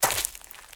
STEPS Leaves, Walk 05.wav